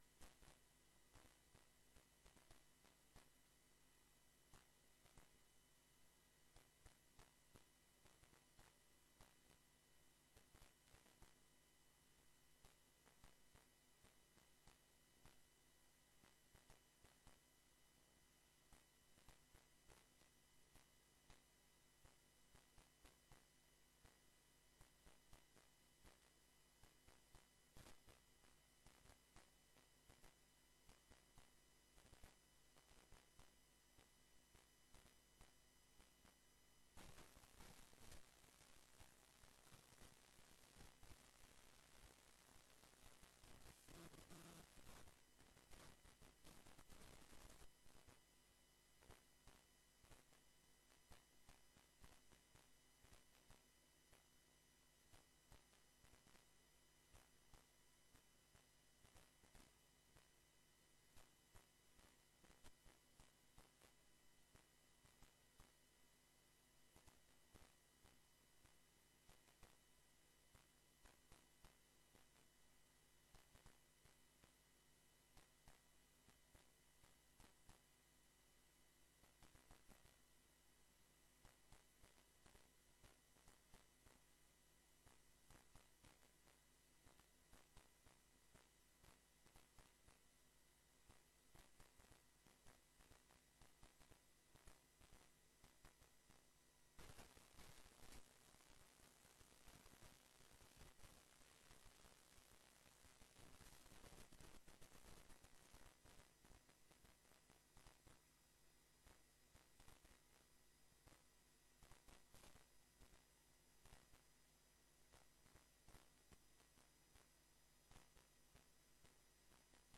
Extra raadsvergadering, deels in openbaarheid, deels in beslotenheid.